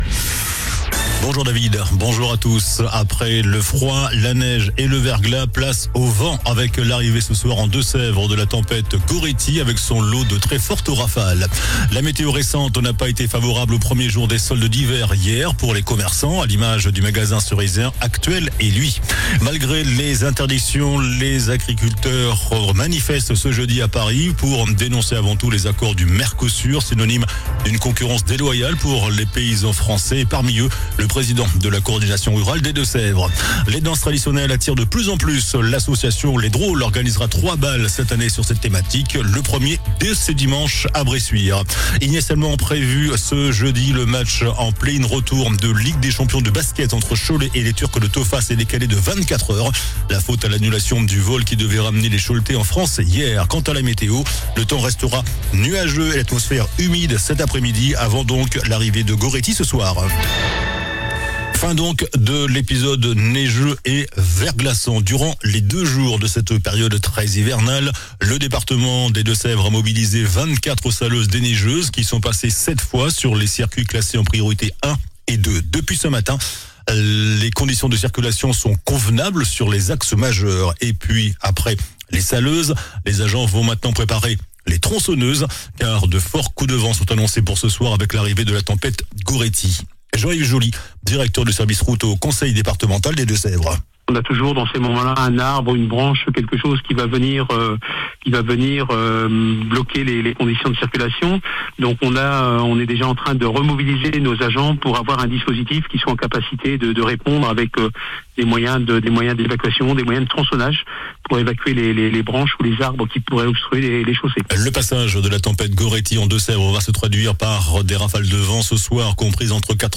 JOURNAL DU JEUDI 08 JANVIER ( MIDI )